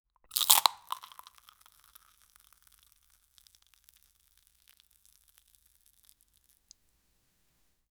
ketchup-and-mustard-being-ypwuka33.wav